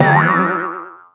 ll_ulti_hit_01.ogg